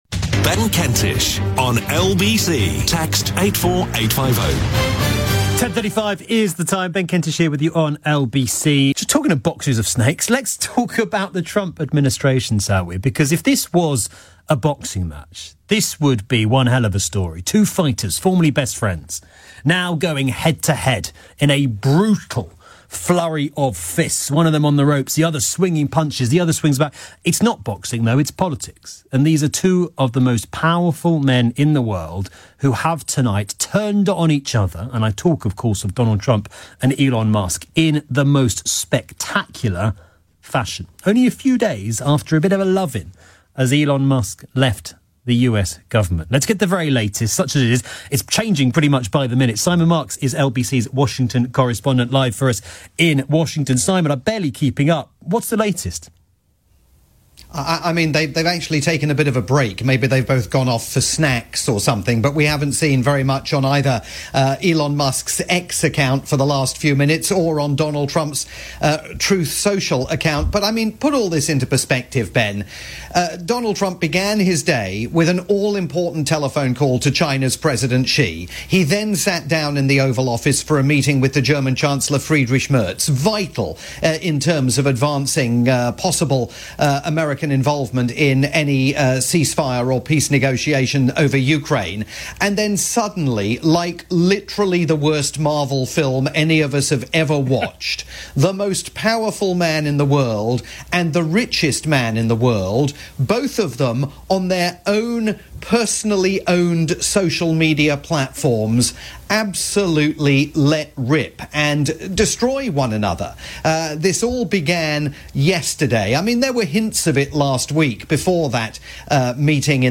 live update
late night programme on the UK's LBC